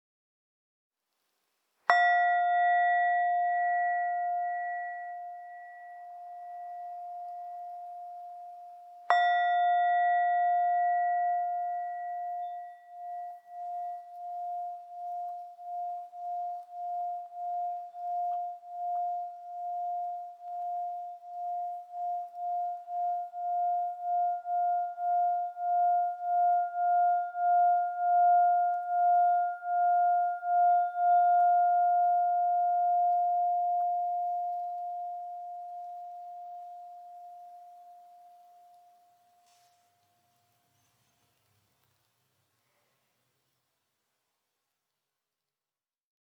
Durch den warmen und entspannenden Klang werden weltweit sehr häufig Klangschalen in Meditiationspraxen oder Yogastudios eingesetzt. Die Ornamental Serie Klangschale wird in Indien aus einer speziellen Messinglegierung gegossen und mit einem handbemalten Ornament verziert.